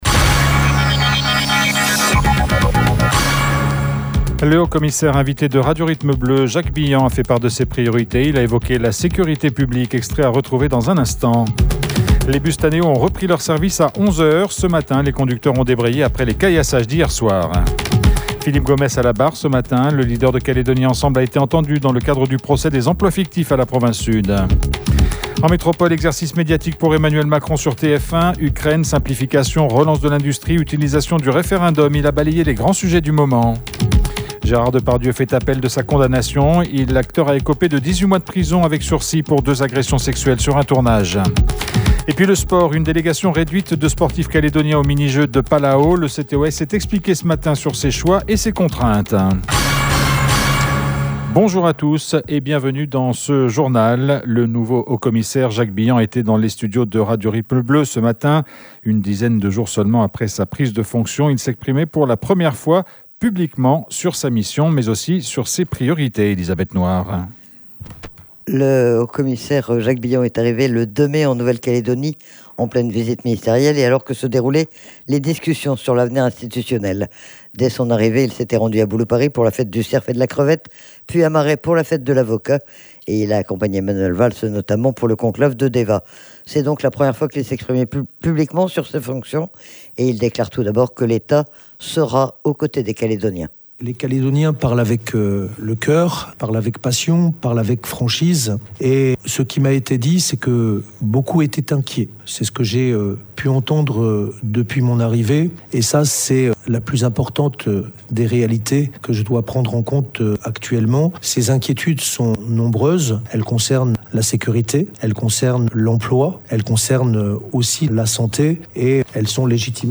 Le Haut-Commissaire Jacques Billant était ce matin notre invité. Il a pris ses fonctions il y a une dizaine de jours, et il a été interrogé sur ses premières impressions sur la Nouvelle-Calédonie, sur la situation du territoire, un an après le début des violences insurrectionnelles et sur ses priorités.